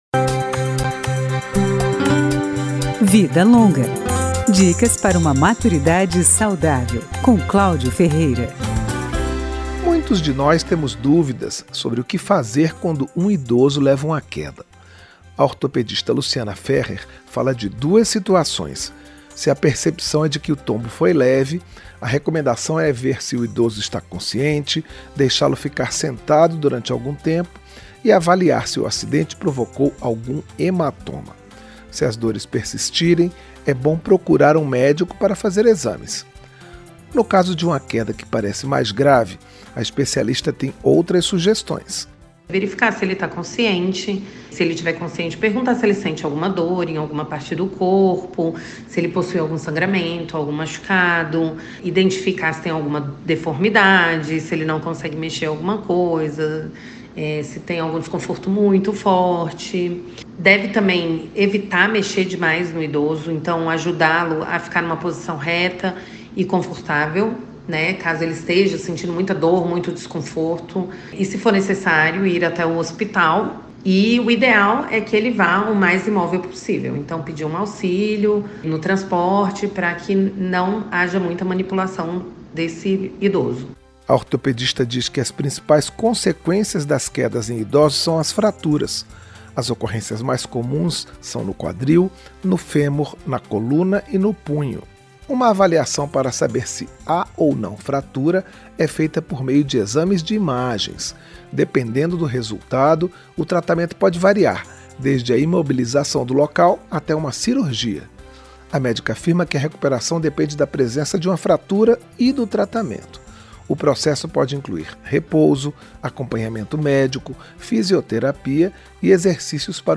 Dicas sobre como envelhecer bem. Profissionais de várias áreas falam sobre alimentação, cuidados com a saúde, atividades físicas, consumo de drogas (álcool, cigarro) e outros temas, sempre direcionando seus conselhos para quem tem mais de 60 anos.